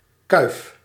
Ääntäminen
Ääntäminen : IPA: [ˈkʀoː.nə] Tuntematon aksentti: IPA: /ˈkʀoː.nən/ Haettu sana löytyi näillä lähdekielillä: saksa Käännös Ääninäyte 1. kuif {m} 2. bloemkroon {m} 3. schuim {n} 4. kroon {m} Artikkeli: die .